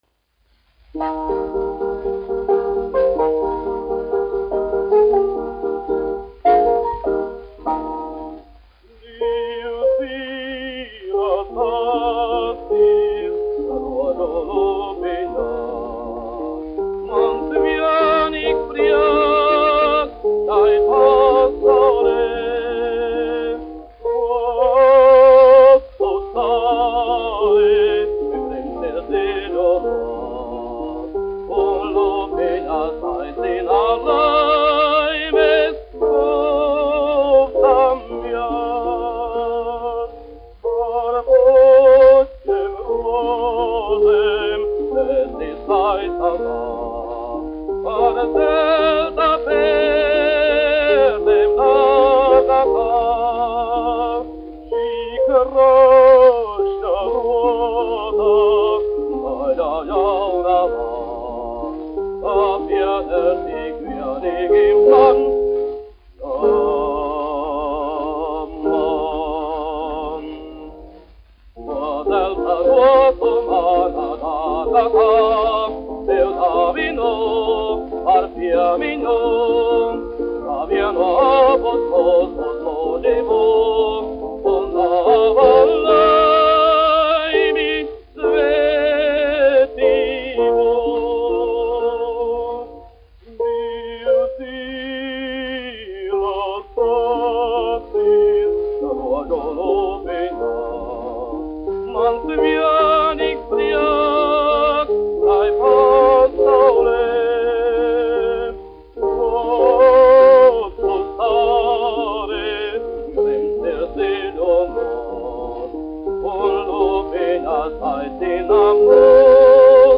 1 skpl. : analogs, 78 apgr/min, mono ; 25 cm
Dziesmas (vidēja balss) ar klavierēm
Skaņuplate
Latvijas vēsturiskie šellaka skaņuplašu ieraksti (Kolekcija)